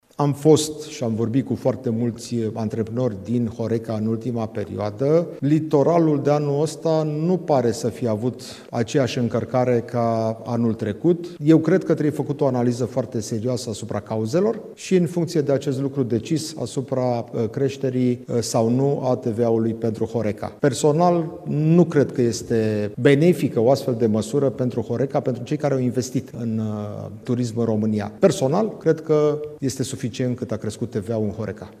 Radu Oprea, secretarul general al Guvernului, a afirmat vineri, în cadrul unei conferințe de presă organizate la sediul PSD, că, în urma discuțiilor avute cu mai mulți antreprenori din domeniul ospitalității, a constatat o reducere a numărului de turiști pe litoral comparativ cu sezonul trecut. El a subliniat că majorarea TVA la 21% nu ar fi oportună în acest context.